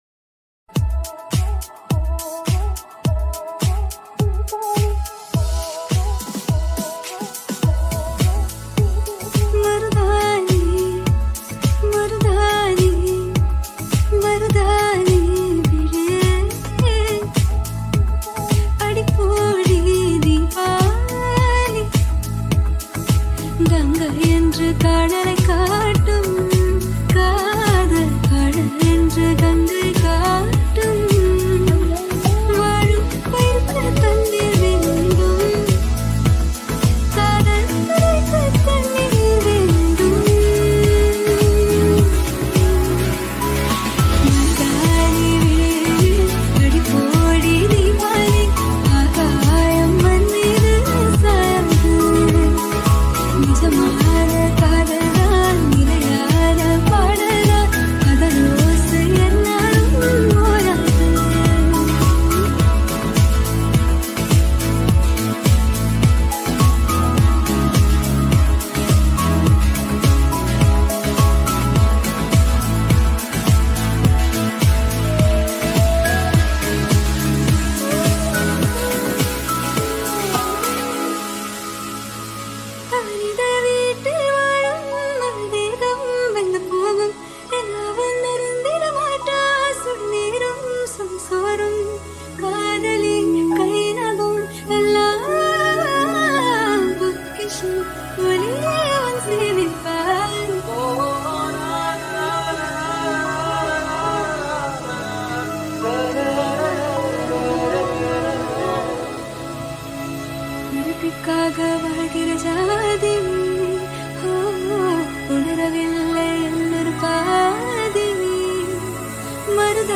Progressive House Remake